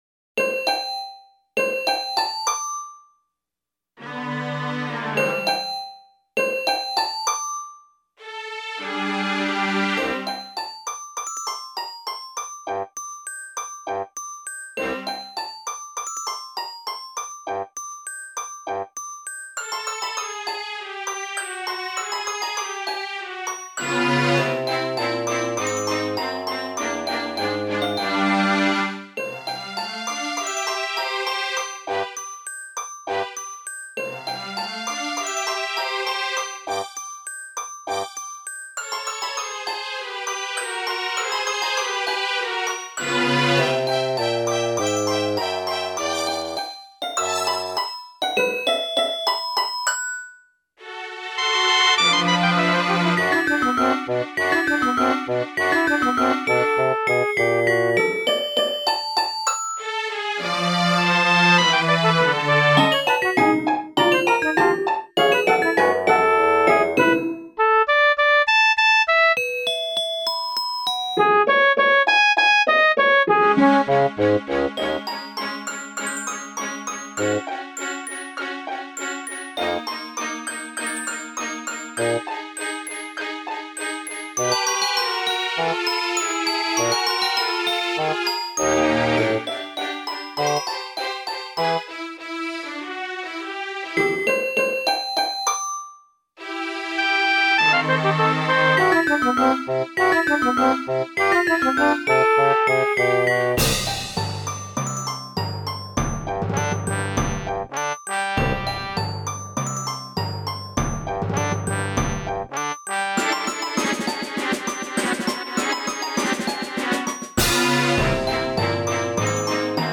C'est sur GBA, et c'est assez robotique.